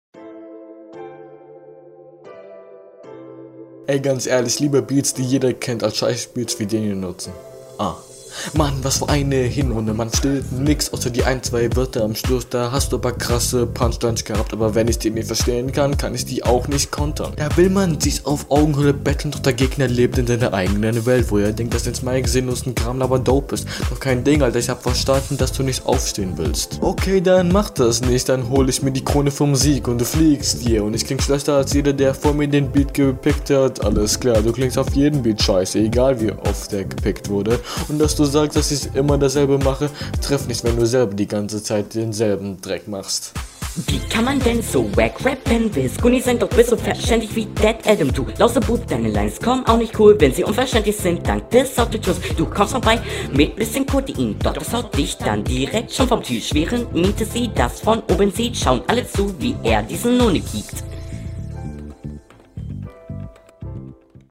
Was direkt auffällt ist die Nichtvorhandene Mische. Beat sehr leise.